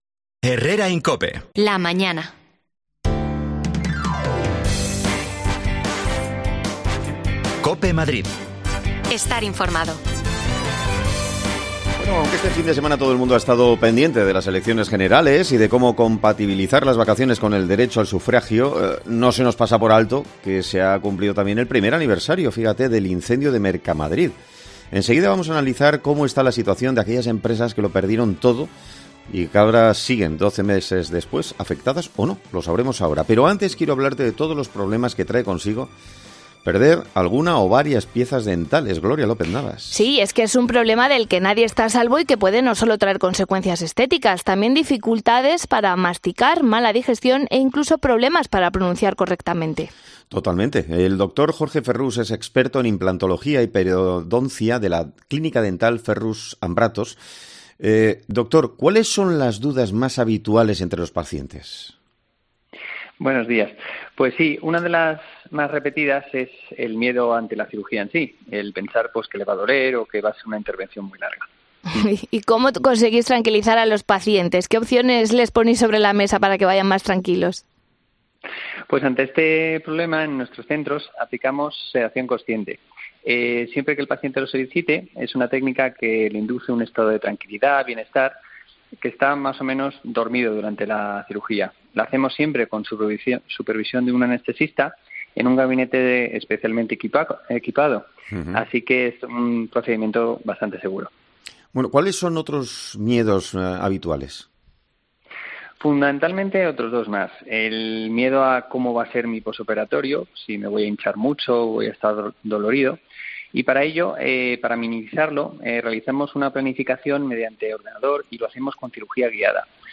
Recordamos el doloroso suceso y la tragedia que se vivió con el incendio de Mercamadrid hace justo 1 año. Uno de sus trabajadores explica como está ahora la situación
Las desconexiones locales de Madrid son espacios de 10 minutos de duración que se emiten en COPE, de lunes a viernes.